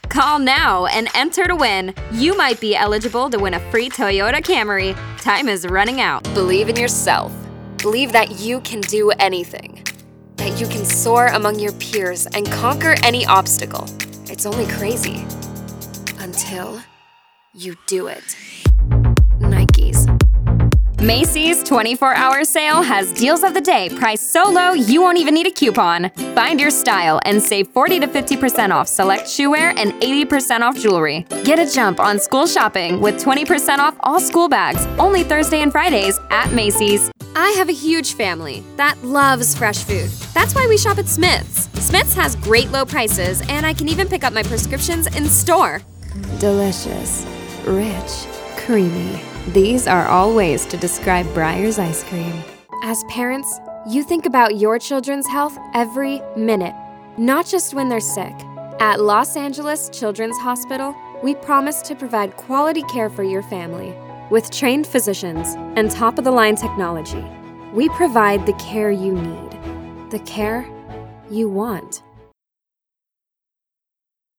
Commercieel, Jong, Natuurlijk, Opvallend, Veelzijdig
Corporate